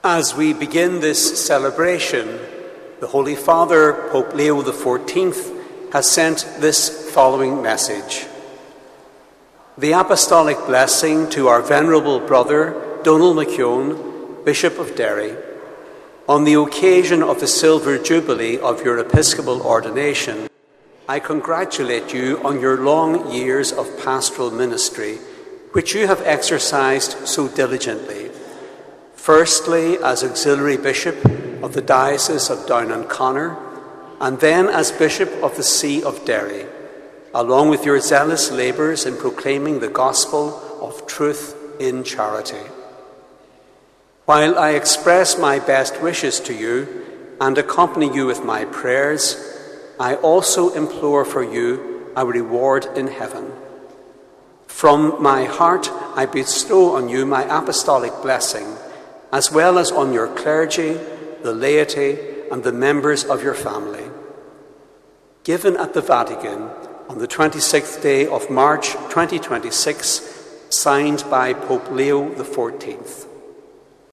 The Bishop of the Diocese of Derry was honoured at a Mass in St Eugene’s Cathedral yesterday.
A message from Pope Leo XIV was also read